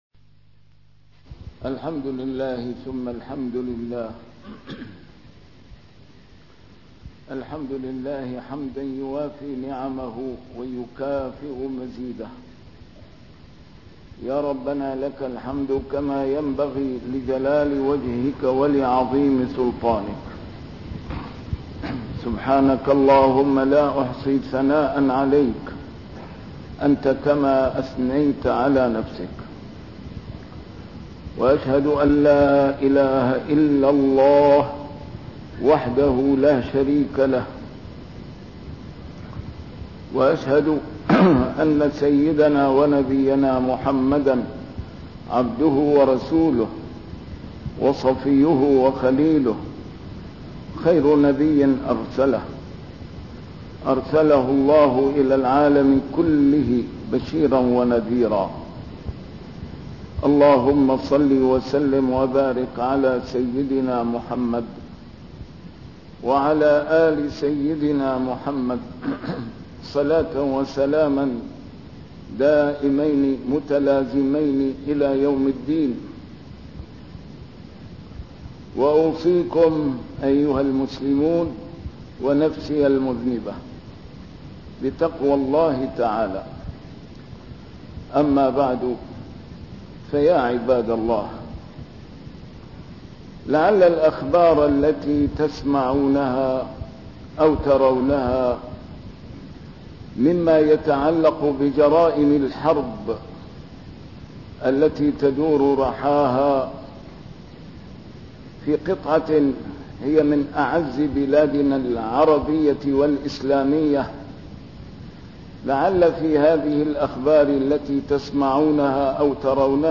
A MARTYR SCHOLAR: IMAM MUHAMMAD SAEED RAMADAN AL-BOUTI - الخطب - الظالم سوط الله في الأرض ينتقم به ثم ينتقم منه